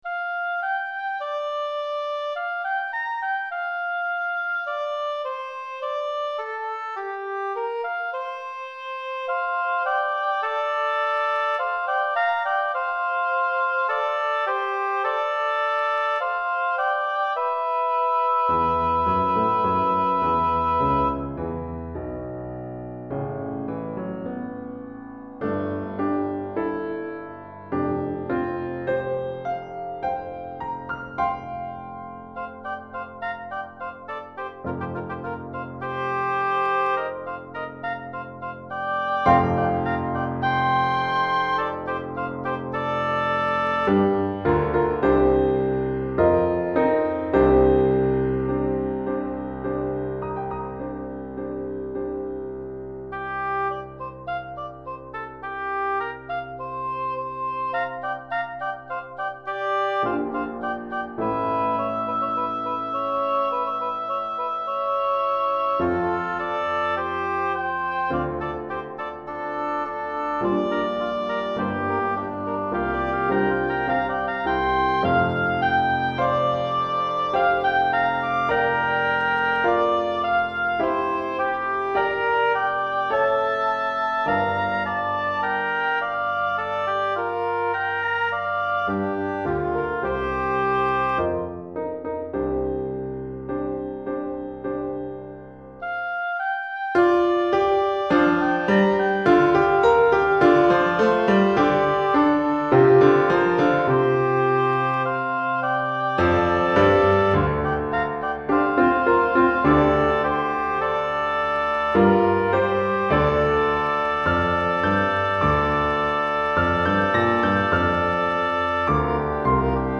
Pour 2 hautbois et piano DEGRE FIN DE CYCLE 1